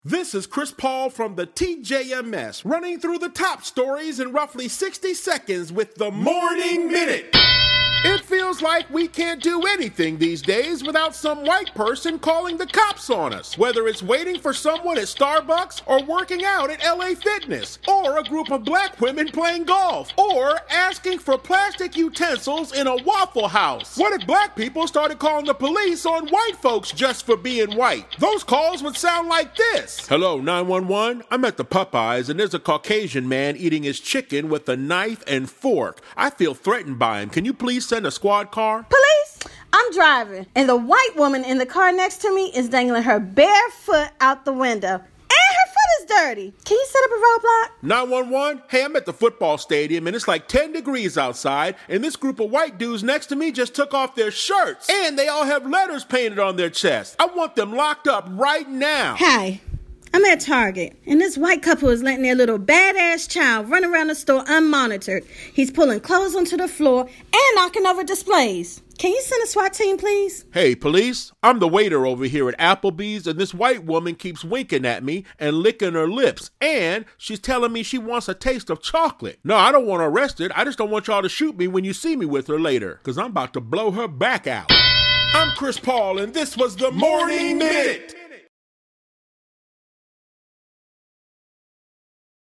comedian